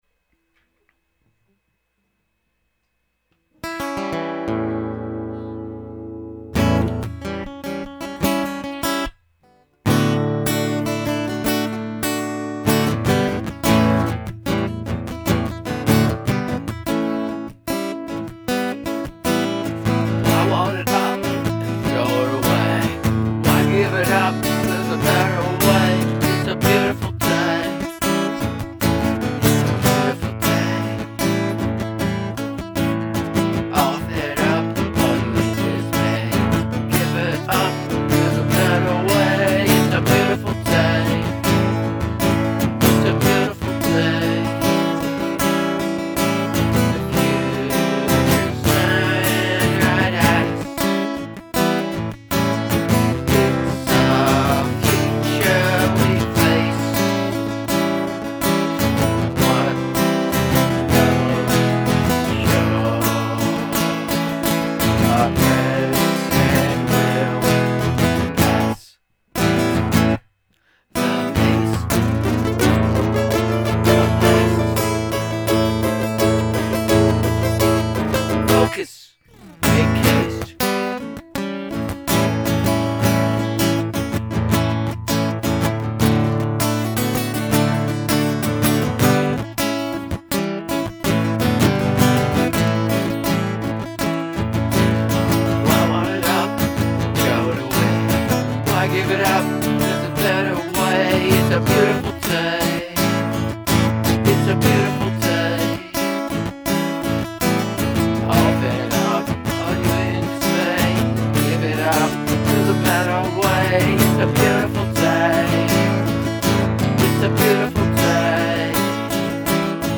Chords: A7 D6 / D G A / C A / C E A
80 to 124 BPM Instrumentation: Vocals, Electric Guitar, Acoustic Guitar, Keyboards (Yamaha PSR740, Korg PS60, MicroKorg) An improvisational song written and recorded on-the-fly as a digital 4-track